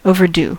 overdue: Wikimedia Commons US English Pronunciations
En-us-overdue.WAV